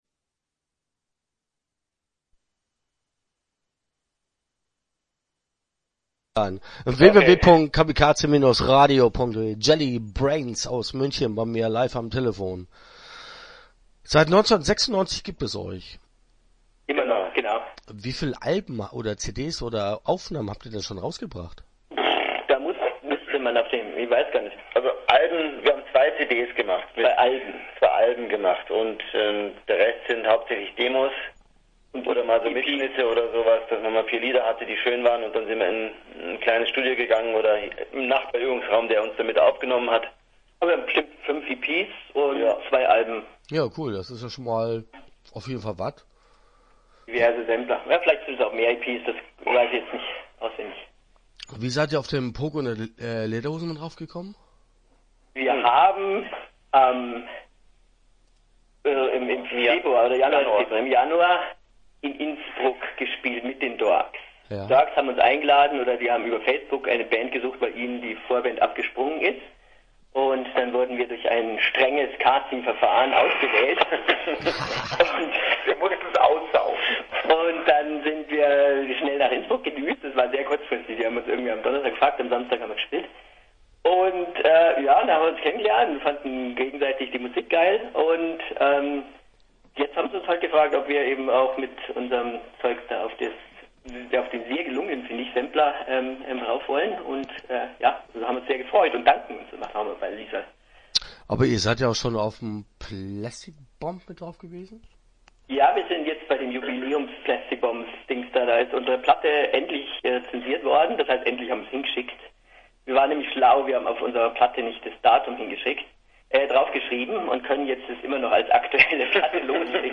Start » Interviews » Jelly Brains